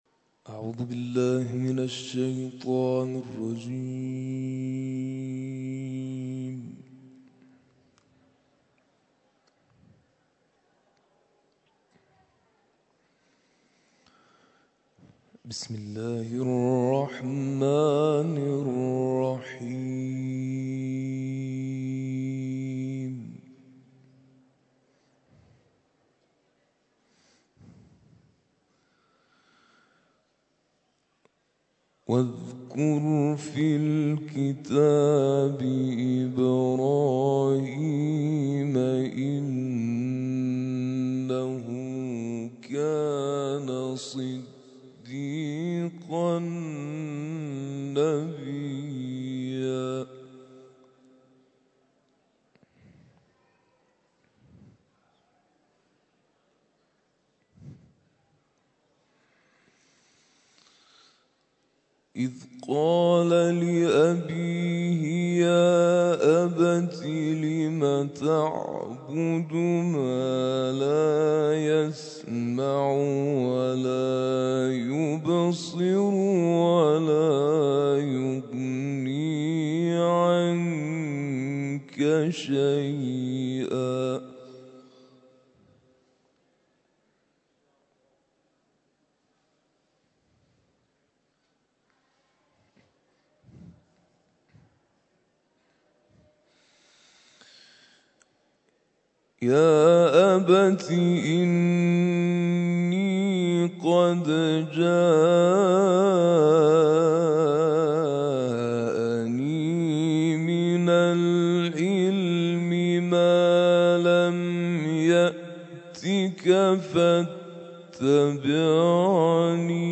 تلاوت مغرب روز جمعه
تلاوت قرآن کریم